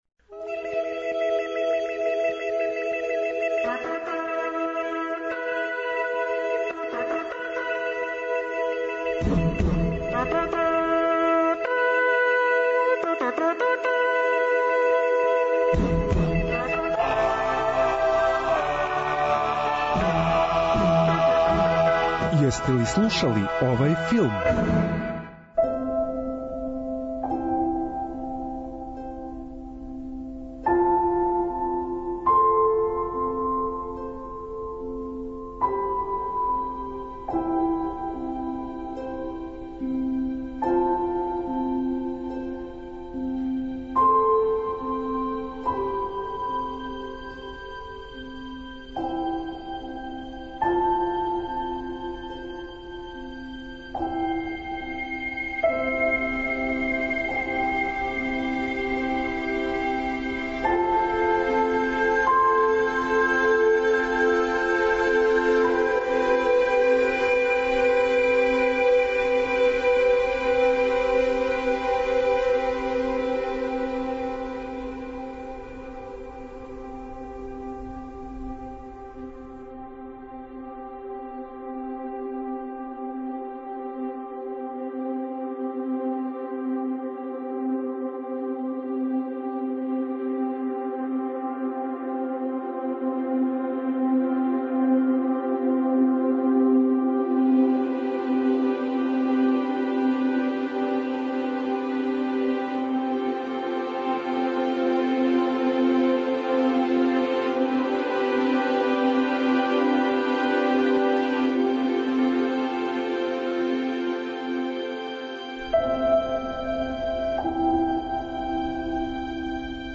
Филмска музика и филмске вести.